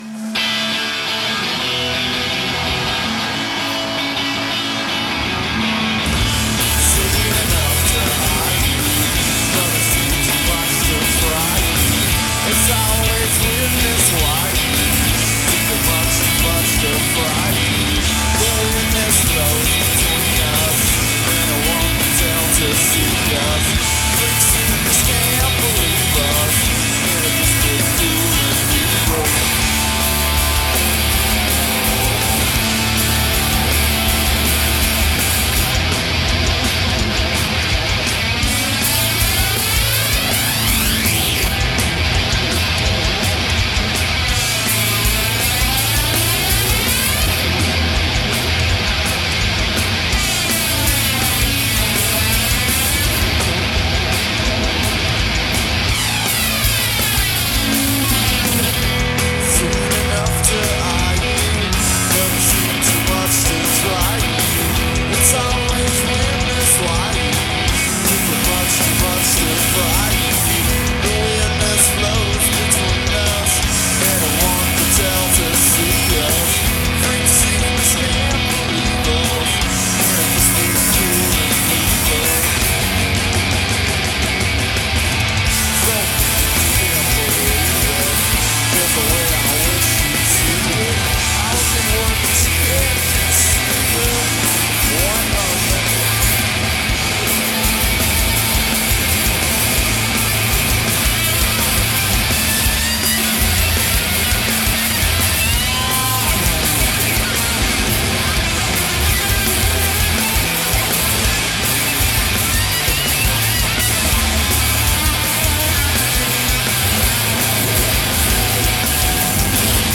guitar
bass
drums
alternative rock band
droning vocals